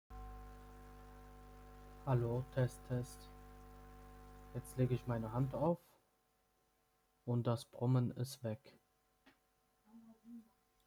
Headset Brummen am Laptop
Hallo zusammen, ich benutze eine Externe USB-Soundkarte am Laptop und habe ein Headset angeschloßen. Es besteht ein dauerhaftes Brummen, aber sobald ich meine Hand über den USB-Anschluß lege verschwindet das Brummen.